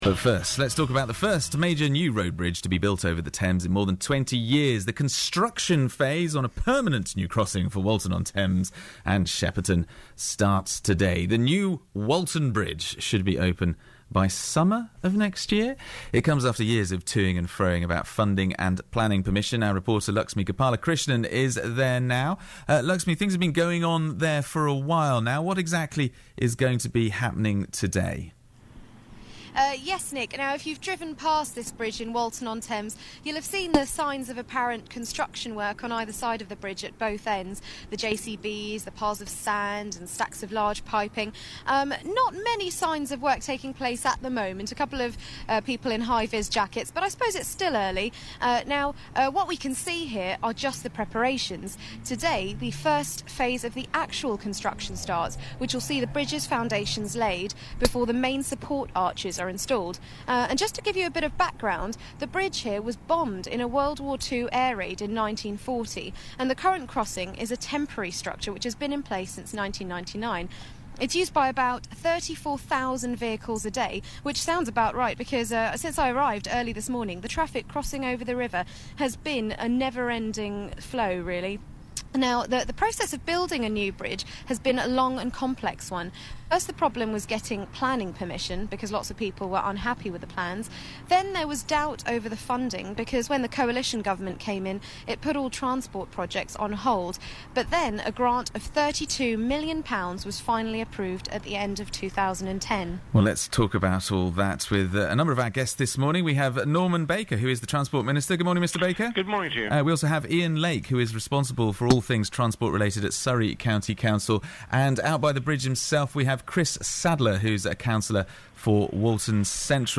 Ian Lake and Norman Baker interviewed about new Walton bridge
ian-lake-walton-bridge-interview.mp3